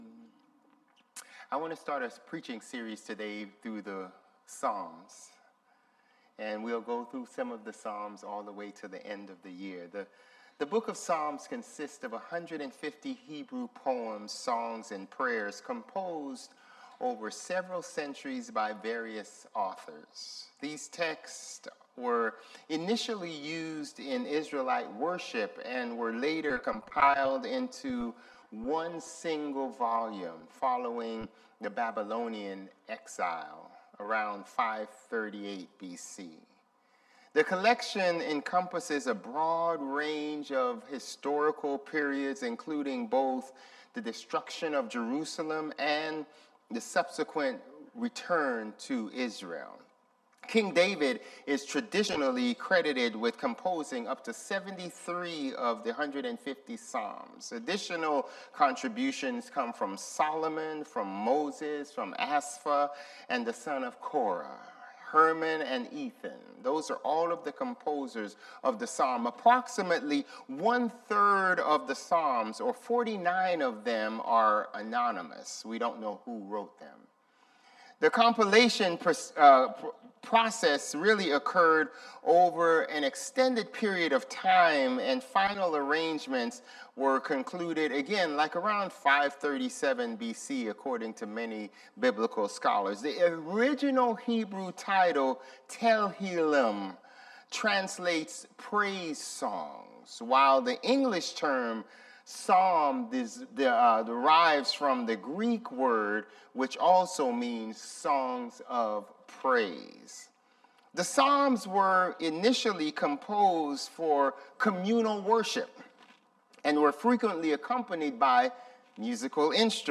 Sermons | Bethel Lutheran Church
October 19 Worship